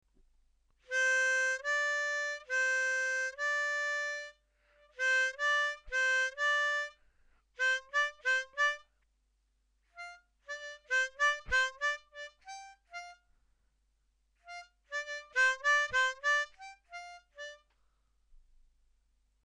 Together, the main and the harmony lines sound like this..